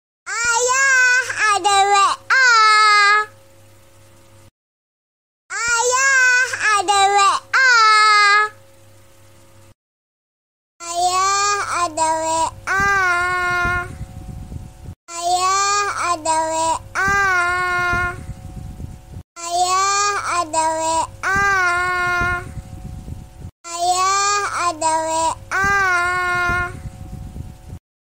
Nada dering WA (Ayah ada WA masuk) Nada dering Ayah telepon
Kategori: Nada dering
Keterangan: Ini nada dering bayi lucu banget yang lagi hits di TikTok.